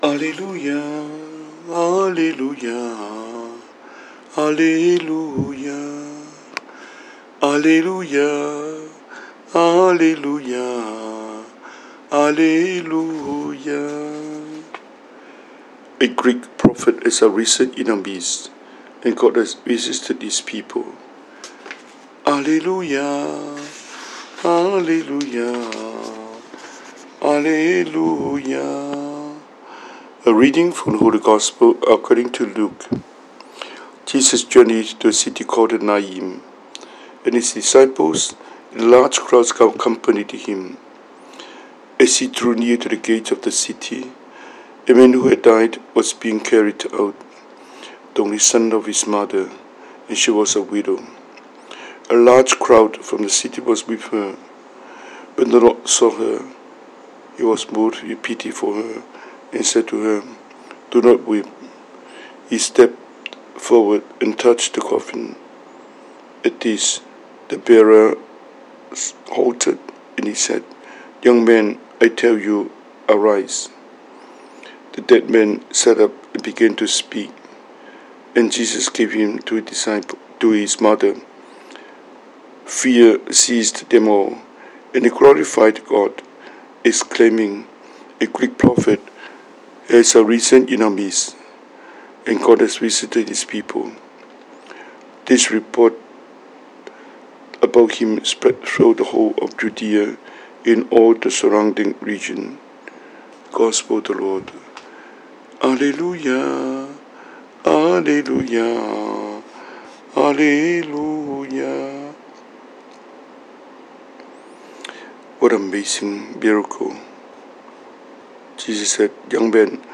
Cantonese Homily,